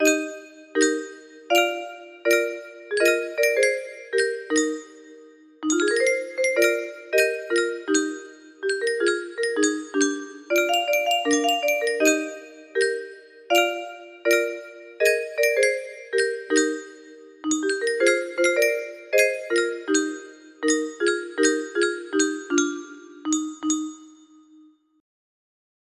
LGW music box melody
Full range 60